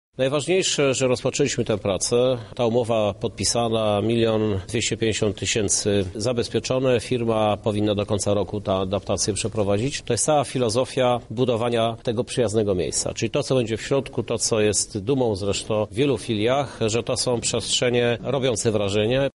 Jesteśmy już na finiszu inwestycji – tłumaczy Prezydent Lublina, Krzysztof Żuk: